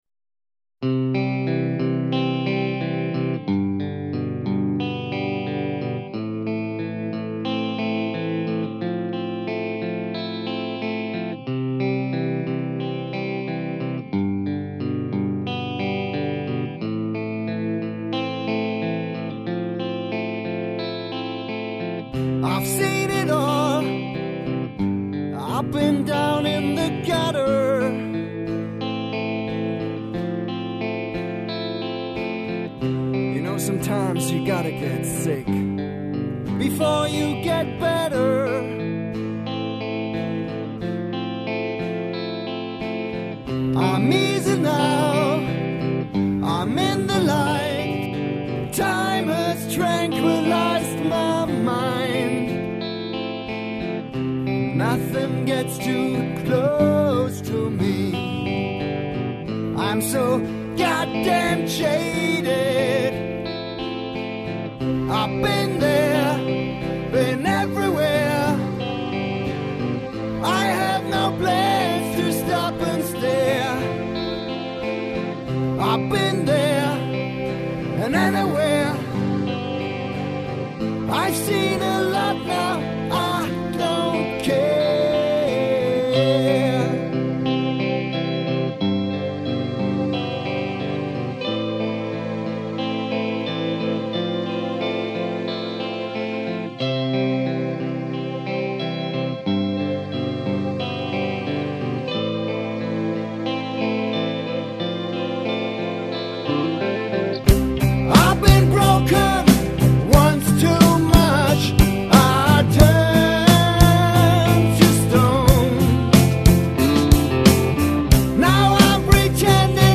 Recorded at MotherMoon Schopfheim and Mellsonic Steinen
Guitars, Keys
Guitar, Vocals